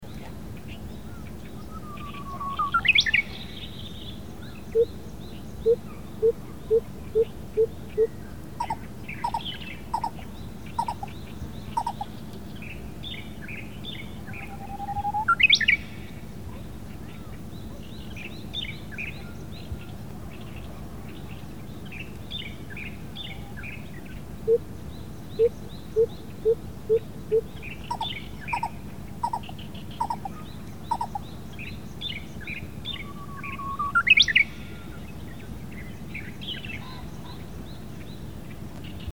25. Lesser Coucal Centropus bengalensis.
At first it was heard singing from deep inside a bush, before climbing up on a dead tree to sun itself and continue singing. No playback was used – just patience.